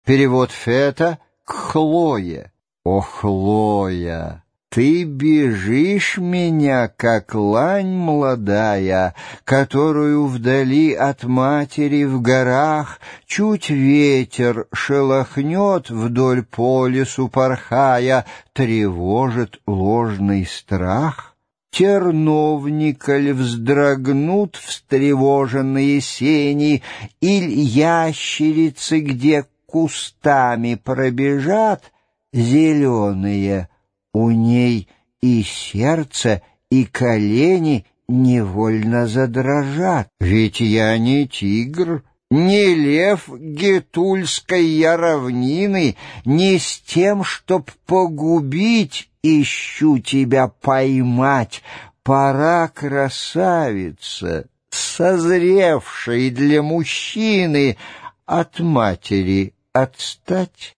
Аудиокнига Избранные оды | Библиотека аудиокниг
Прослушать и бесплатно скачать фрагмент аудиокниги